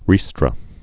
(rēstrə)